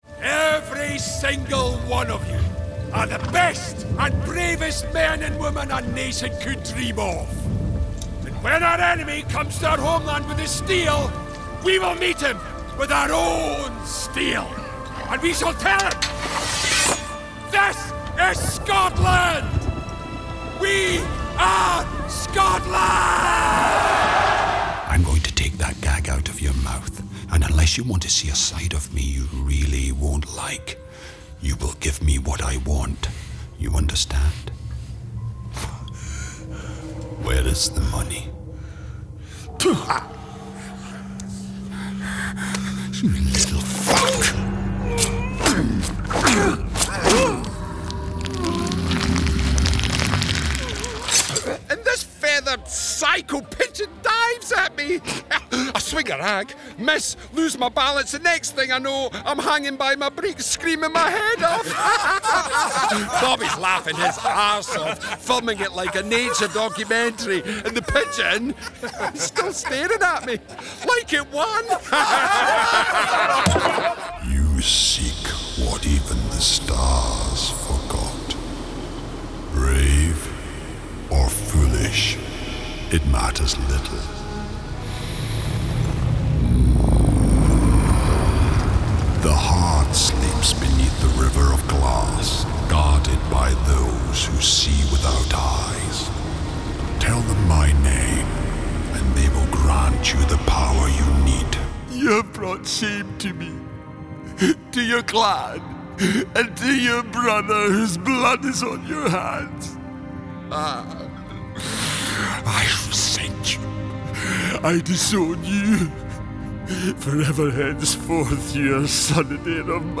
If you’re looking for a versatile Scottish male voiceover to represent your brand and share your passion, I could be the perfect voice for you.
From warm and personable, strong and authoritative, characterful and engaging, I get to the heart of your message.